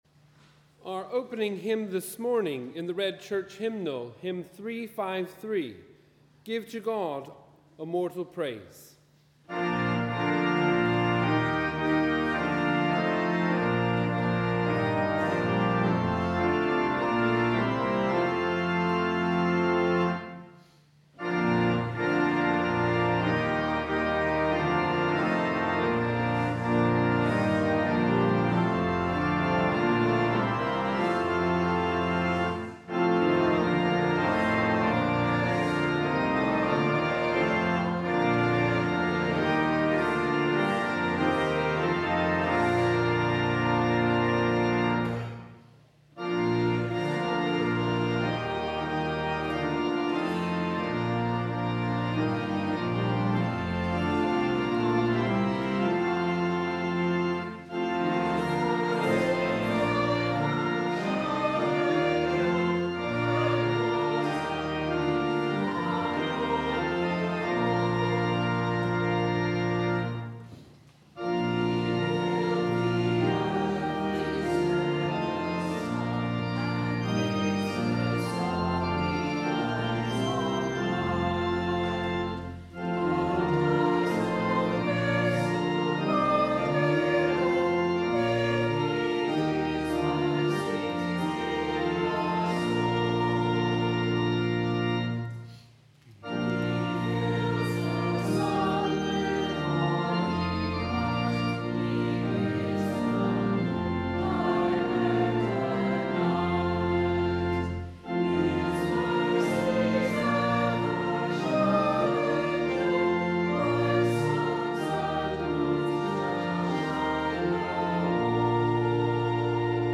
Wherever you are, we welcome you to our service of Morning Prayer on the 14th Sunday after Trinity, which is also the Feast Day of St. Matthew.